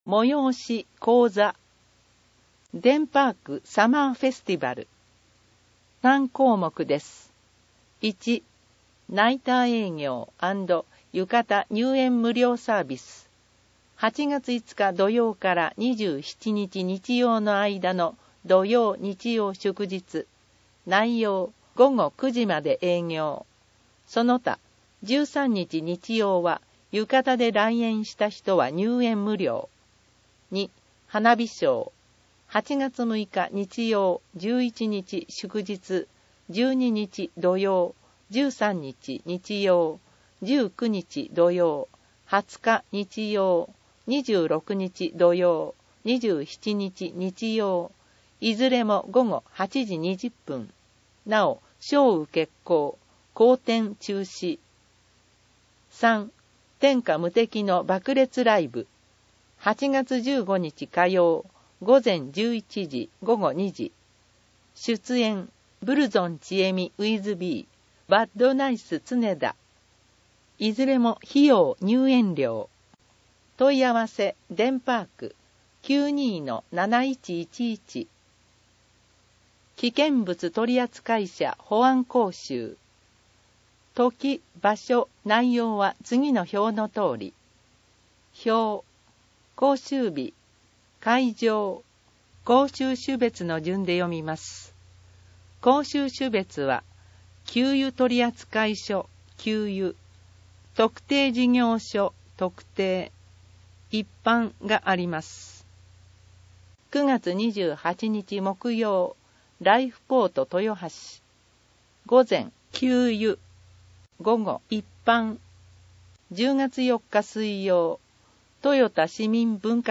広報あんじょうの音声版を公開しています
なお、以上の音声データは、「音訳ボランティア安城ひびきの会」の協力で作成しています。